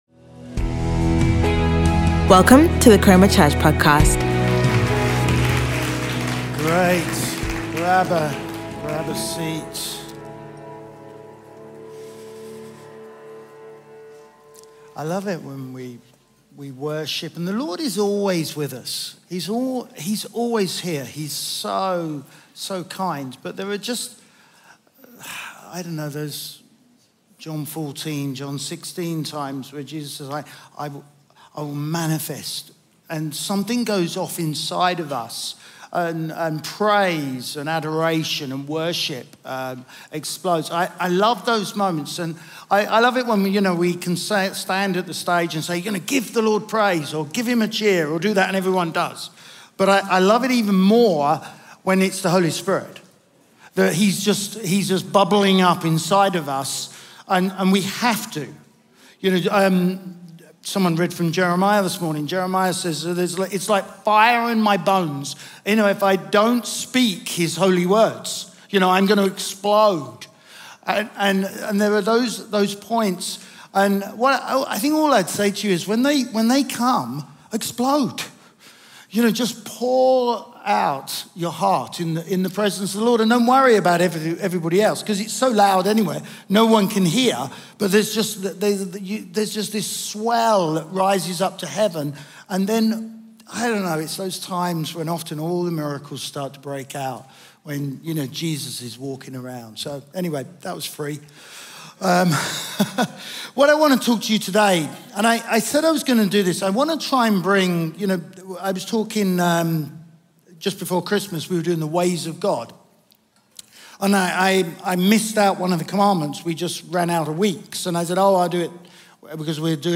Chroma Church - Sunday Sermon Do Not Covet